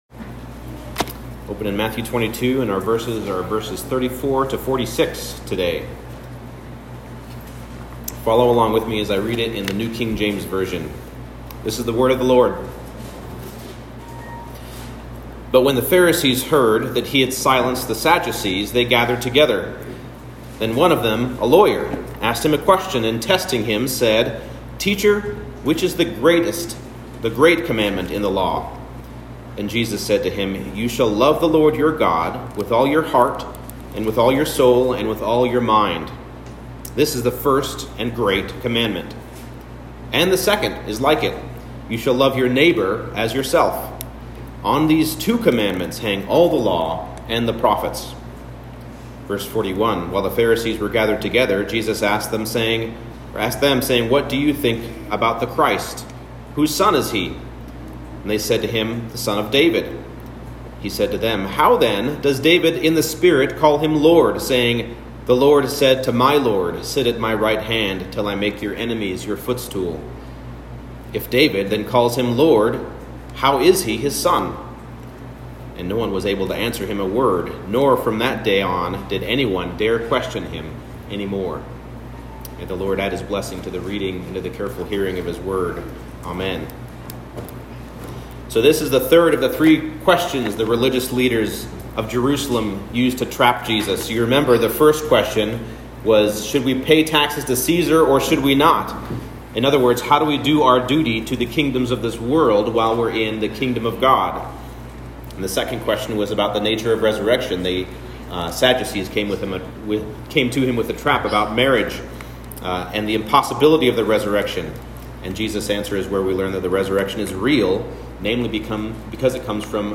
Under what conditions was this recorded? Matthew 22:34-46 Service Type: Morning Service The two most important questions in history are "What does God desire?" and "Who is Jesus Christ?"